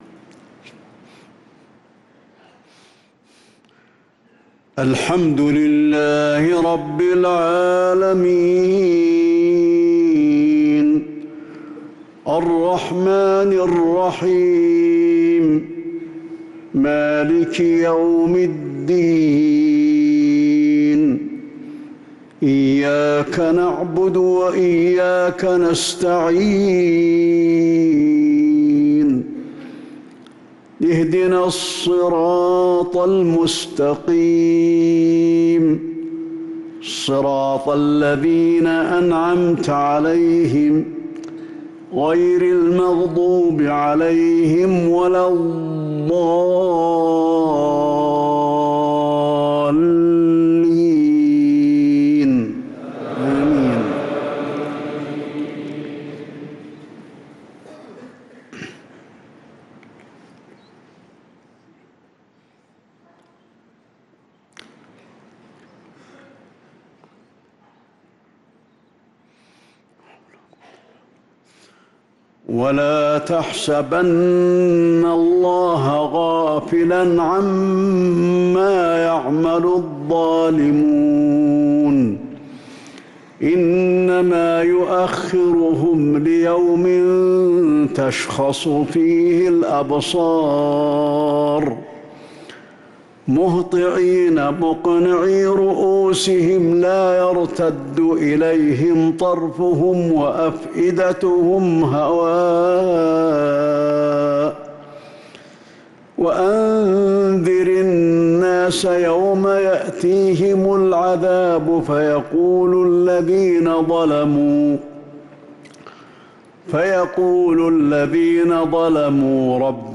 صلاة العشاء للقارئ علي الحذيفي 3 ربيع الآخر 1445 هـ
تِلَاوَات الْحَرَمَيْن .